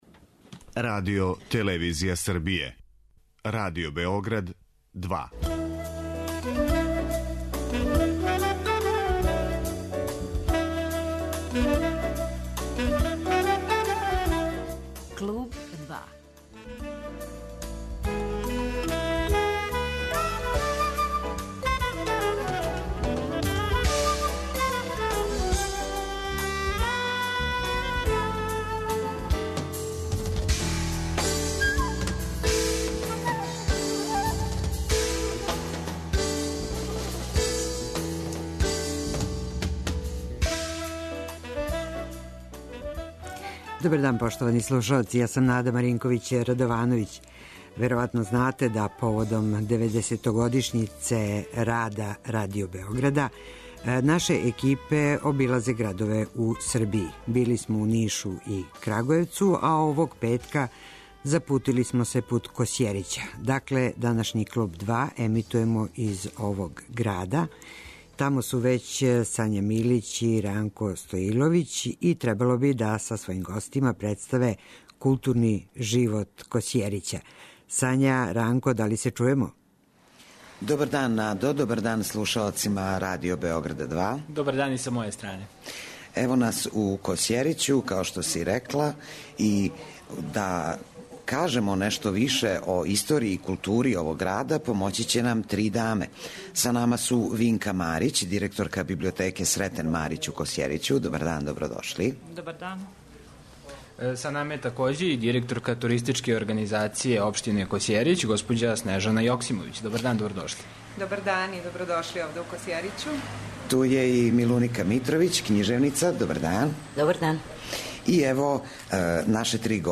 Поводом 90. рођендана Радио Београда, 'Клуб 2' ћемо емитовати уживо из Косјерића.